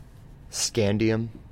Ääntäminen
US GA
IPA : /ˈskændi.əm/